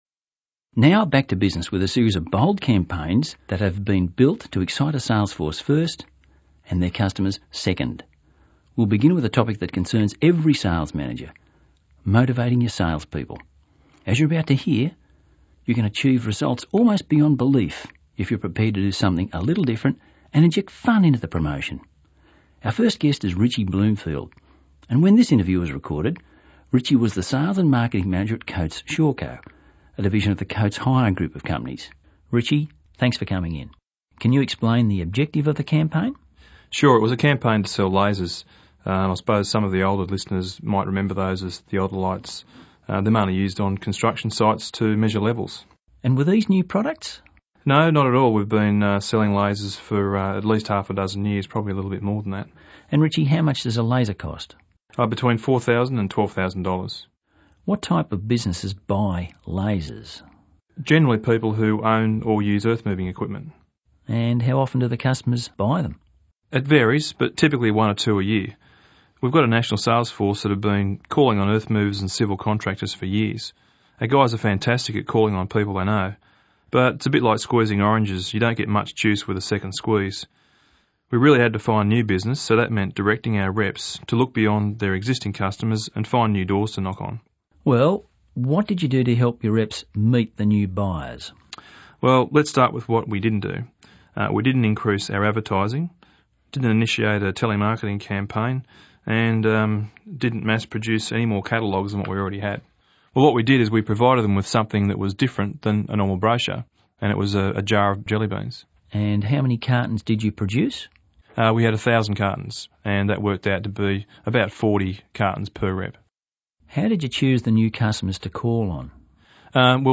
Below are audio interviews recorded with our clients explaining effective sales promotions campaigns.